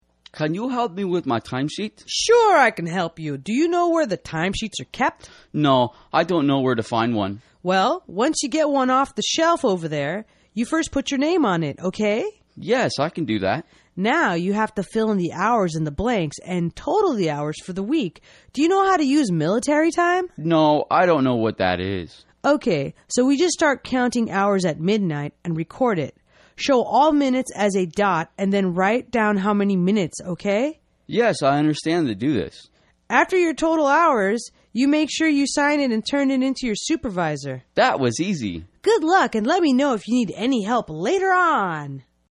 工作英语对话:How to Do a Timesheet(1) 听力文件下载—在线英语听力室